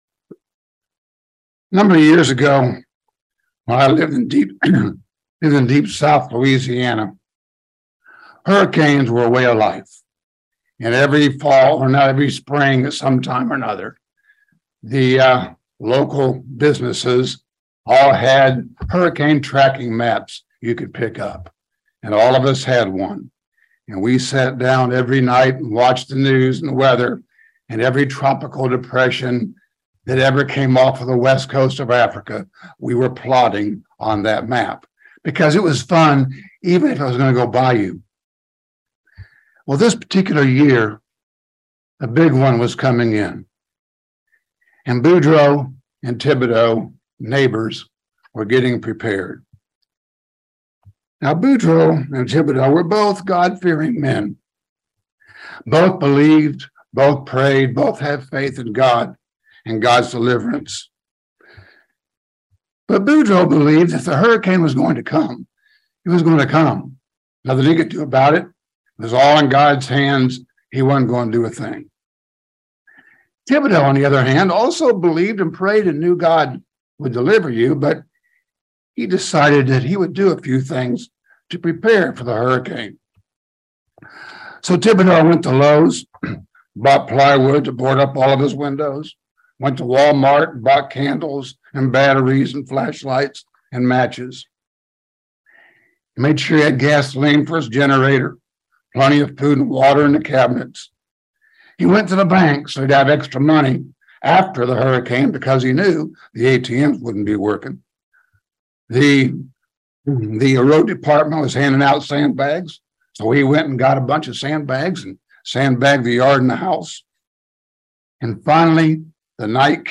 Given in London, KY